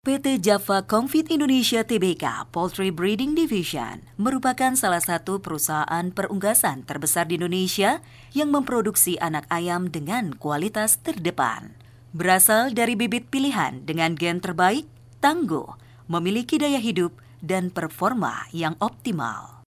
Her voice delivers a one-two punch of strength , authenticity and honest warmth in a neutral Non-regional accent. Her commercial voice has been described as ranging from Smooth Sophisticated Classy and Confident to Warm and Relatable, Approachable, Natural, Comforting, Believable, Conversational, Genuine, Soothing and Sincere. Other Keywords used to describe her voice are: Sassy, Friendly, Dynamic, Energetic, Upbeat, Fun, Animated, Happy, Playful and High Energy.